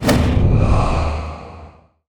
blood_rite_cast.wav